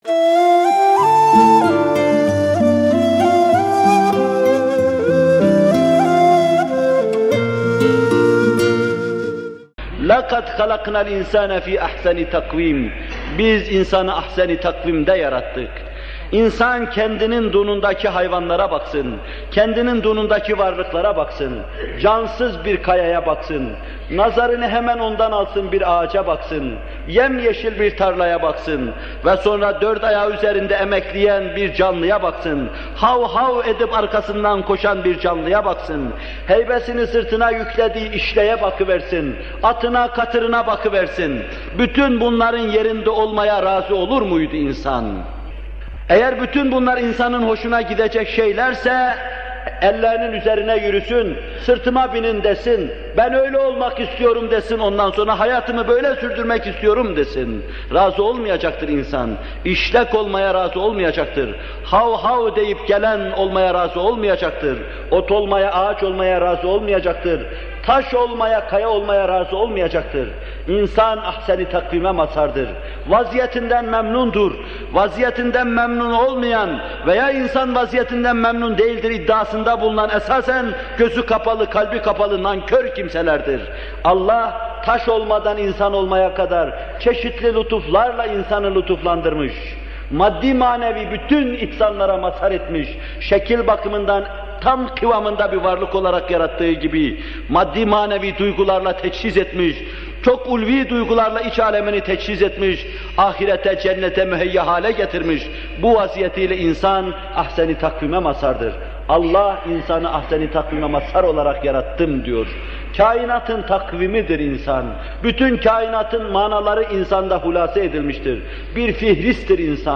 Muhterem Fethullah Gülen Hocaefendi bu videoda Tîn Suresi 4. ayet-i kerimesinin tefsirini yapıyor: